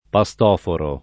pastoforo [ pa S t 0 foro ] s. m.